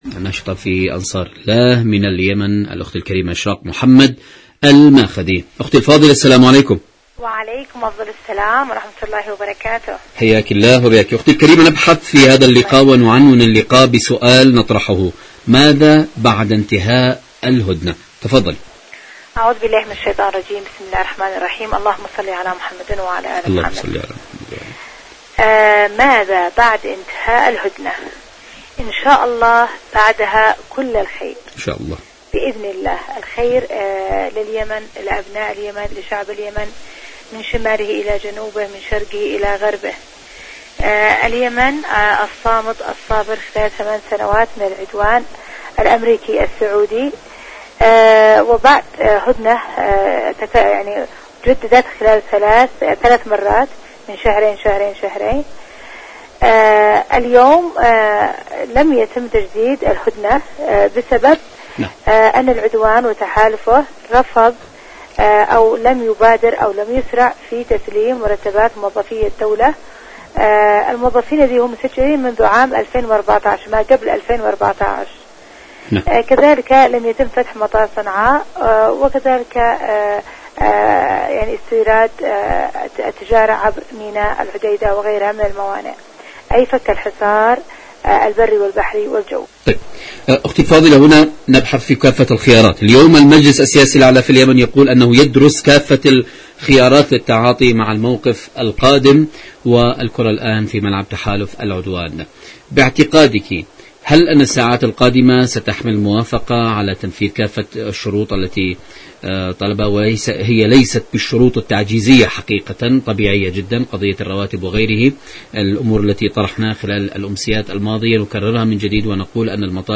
مقابلات برامج إذاعة طهران العربية برنامج اليمن التصدي والتحدي اليمن مقابلات إذاعية العدوان على اليمن الشعب اليمني حركة انصار الله انتهاء الهدنة ماذا بعد انتهاء الهدنة؟